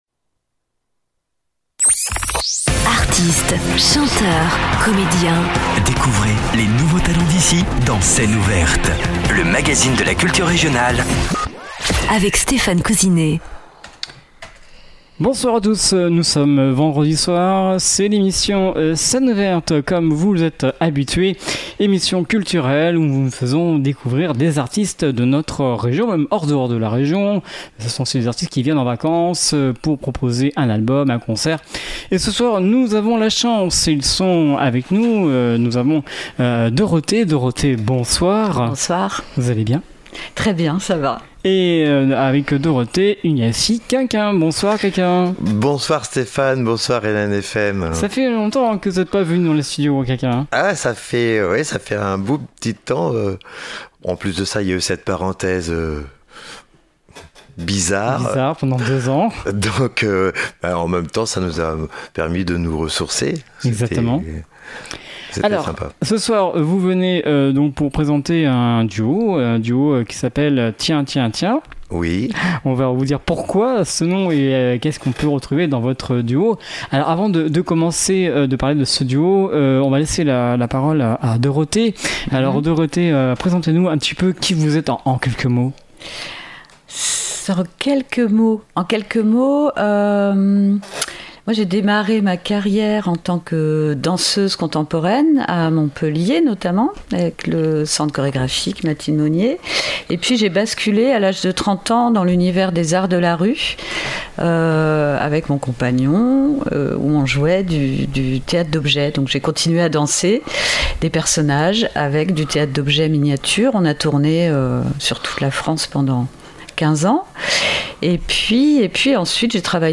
C’est une forme populaire tout public et en mouvement.